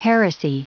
Prononciation du mot heresy en anglais (fichier audio)
Prononciation du mot : heresy